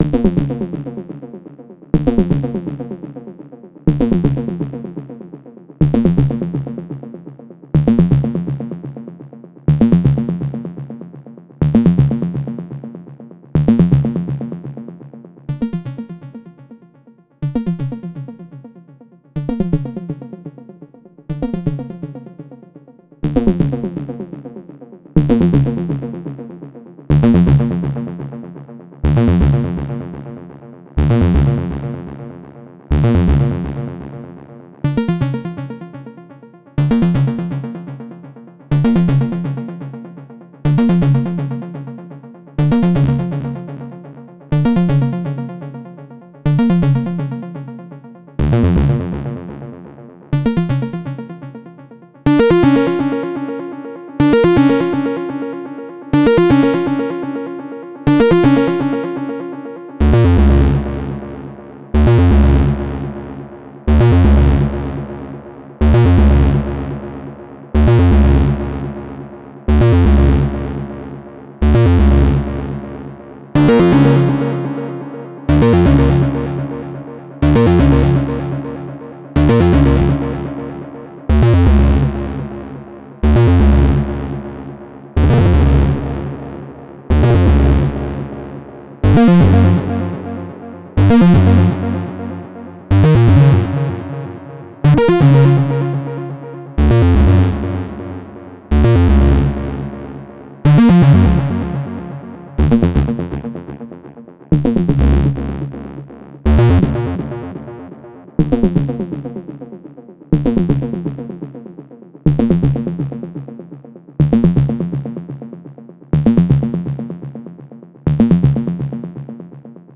Even more FM. Synced and overdriven in variations towards the end.
Edit: Knobs turned: Sync Type, Filter Overdrive, Sync Amount. Nothing else.